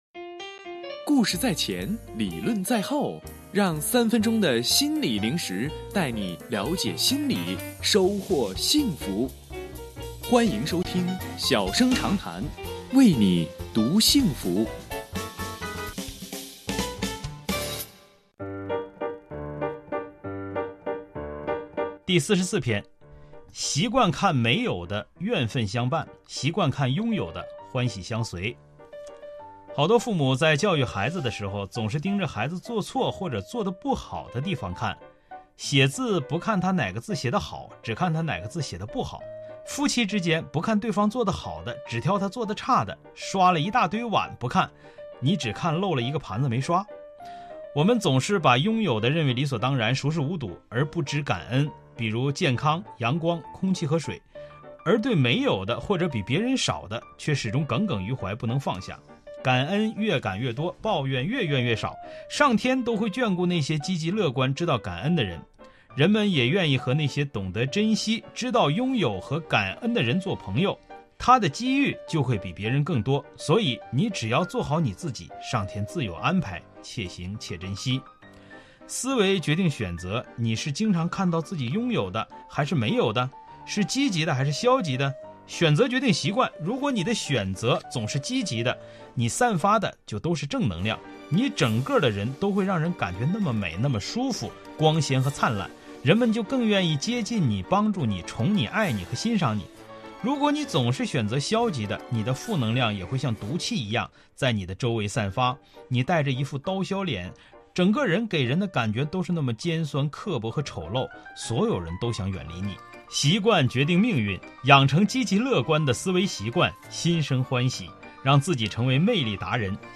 吉林广播电视台 新闻综合广播